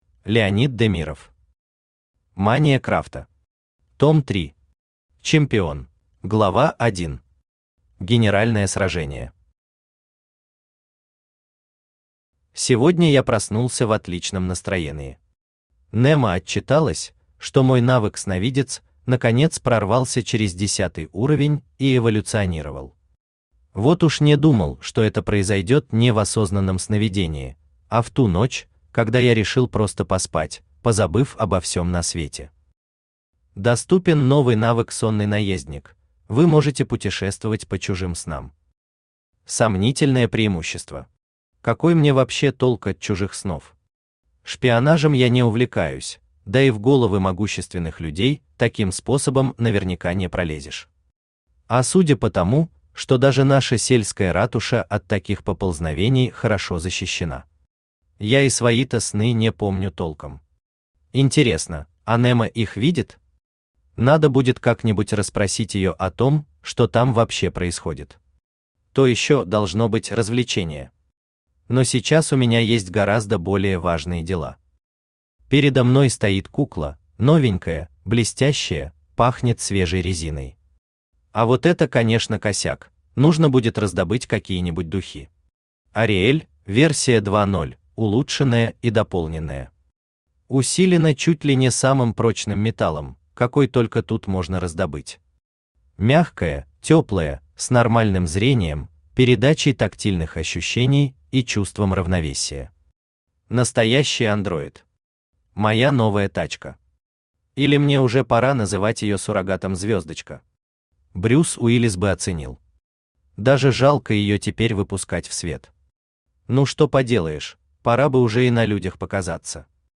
Аудиокнига Мания крафта. Том 3. Чемпион | Библиотека аудиокниг
Чемпион Автор Леонид Демиров Читает аудиокнигу Авточтец ЛитРес.